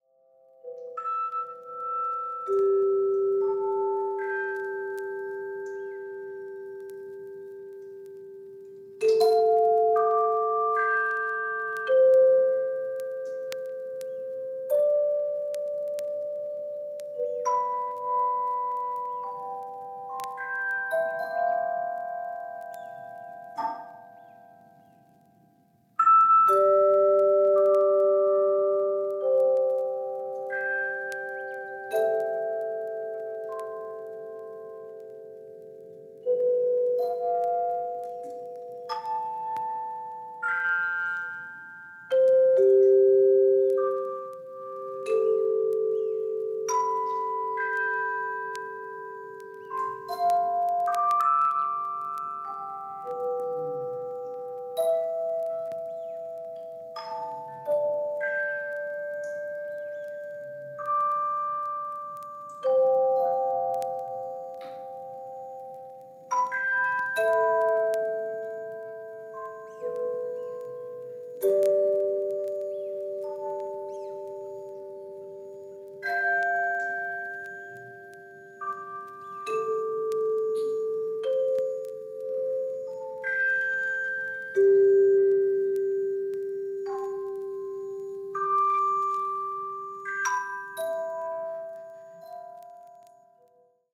による、単音打楽器＜クワイヤーチャイム＞の演奏。
体にしみる音の響きが急がず、慌てず、流れてきます。